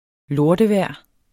Udtale [ ˈloɐ̯də- ]